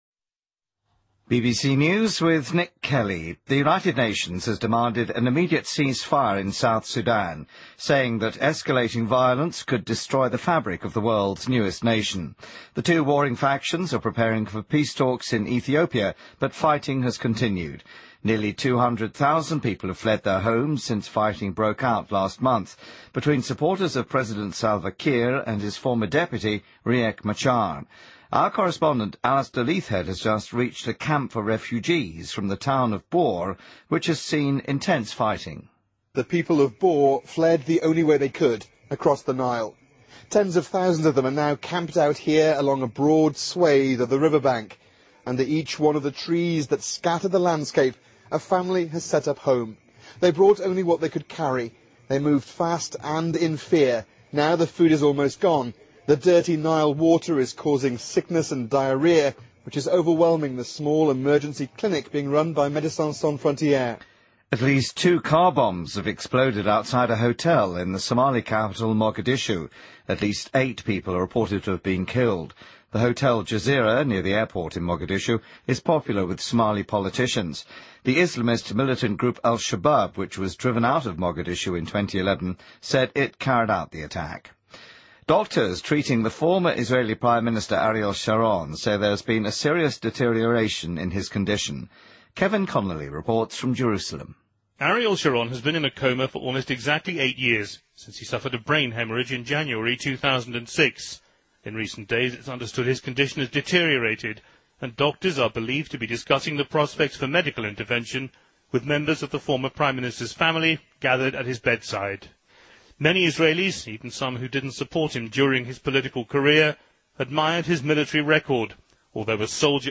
BBC news,2014-01-02